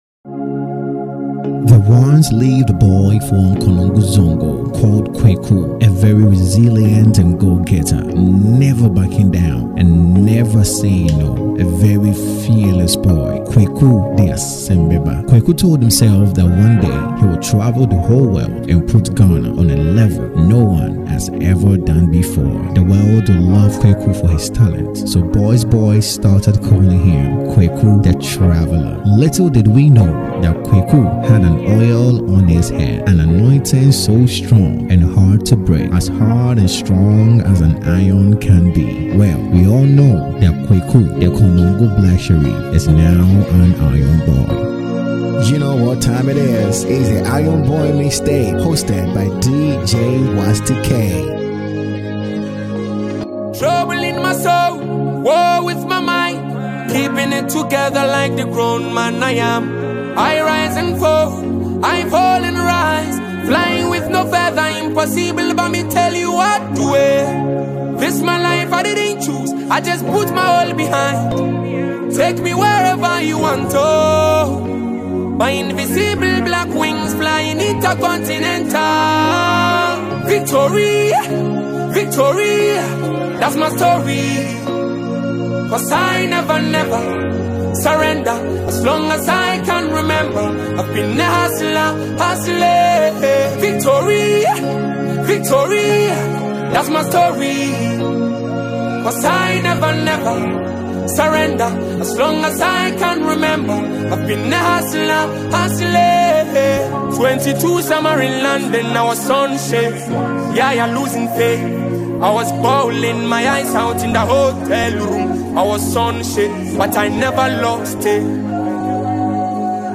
is back with another hit mixtape!
with seamless transitions that will keep you hooked.